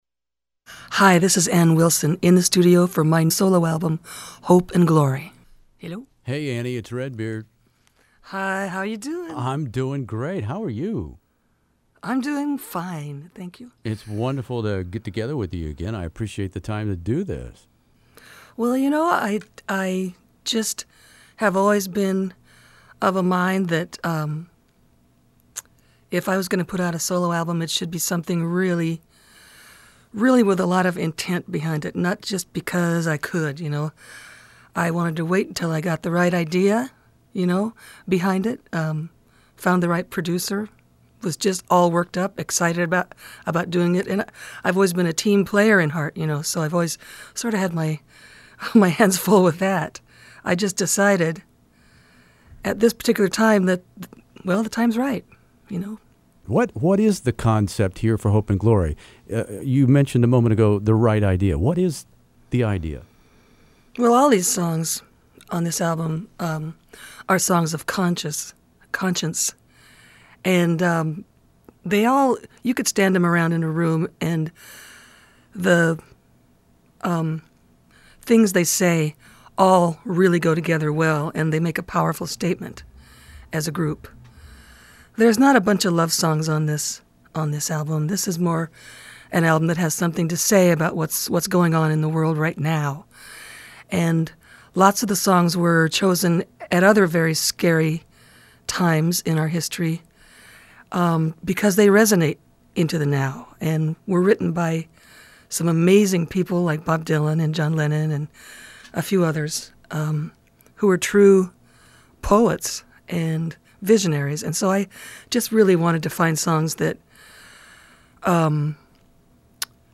Ann Wilson interview "Hope and Glory" In the Studio